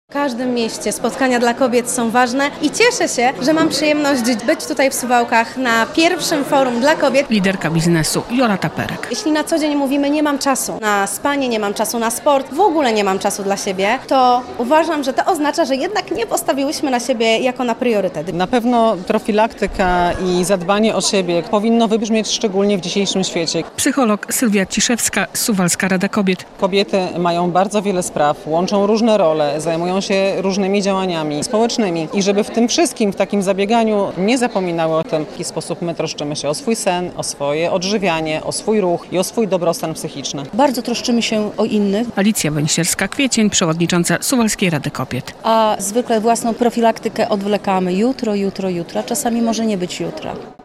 Jak we współczesnym świecie znaleźć równowagę i inspirację do działania? Nad tym debatowały uczestniczki Suwalskiego Forum Kobiet.